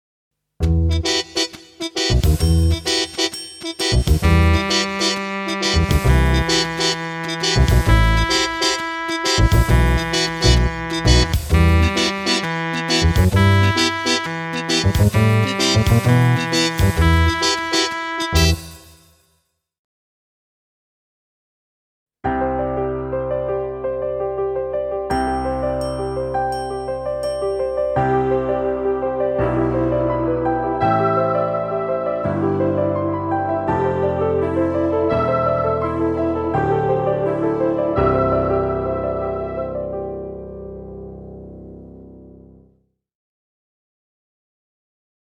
Extra soundmixbegeleidingen voor tutors instrument: